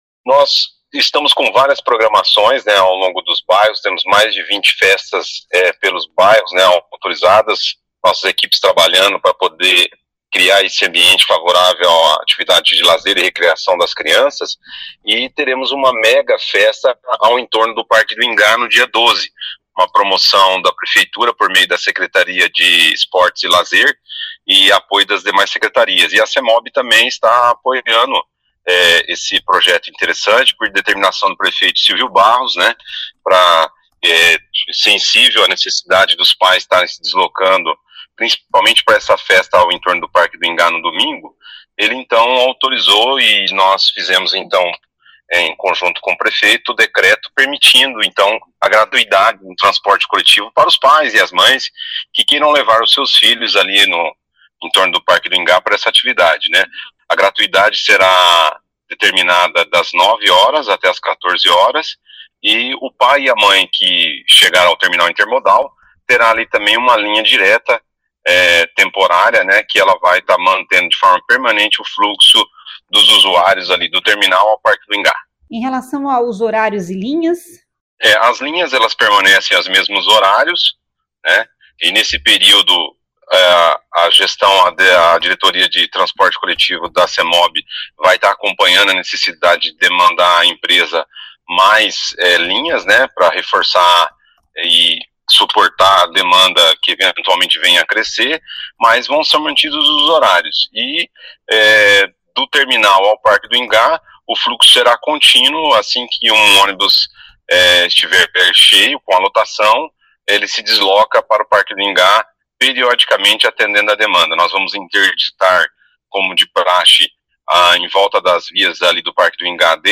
Ouça o que diz o secretário de Mobilidade Urbana, Luciano Brito.